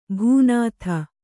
♪ bhū nātha